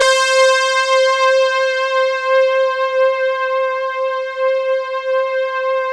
CHORUS BRASS.wav